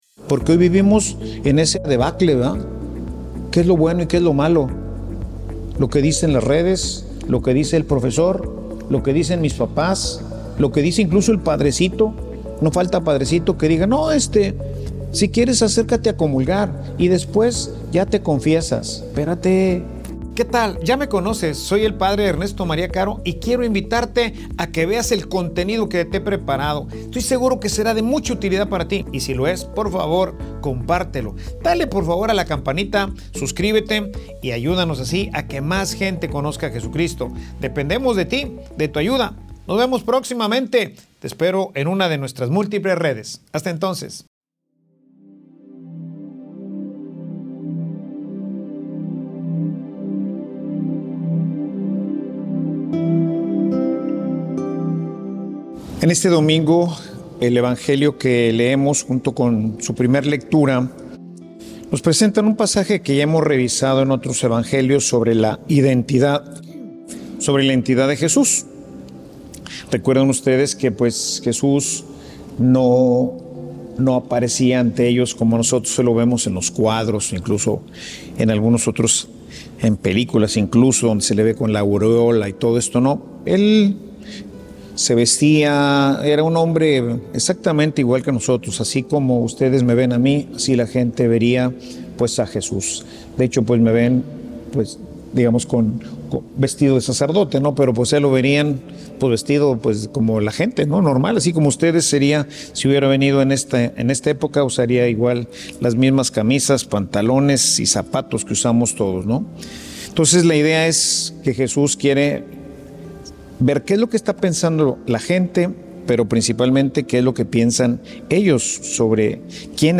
Homilia_Caminar_contra_corriente.mp3